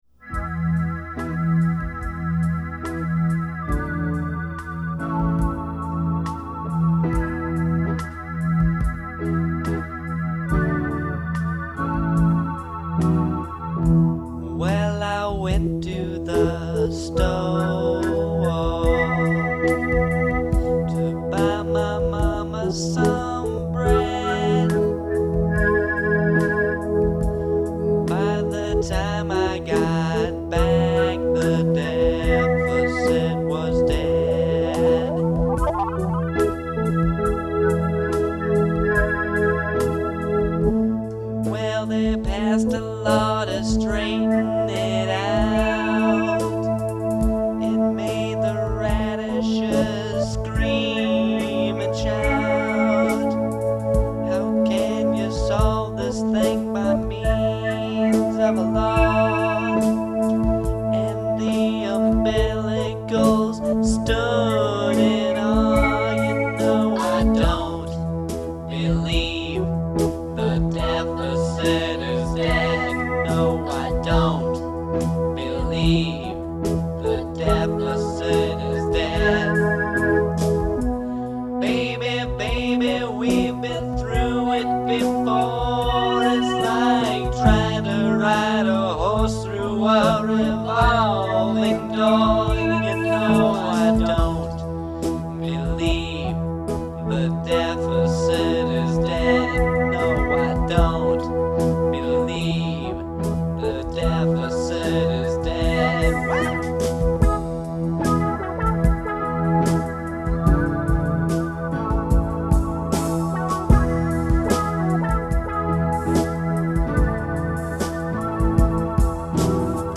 It’s a slow, transformed version
female vocals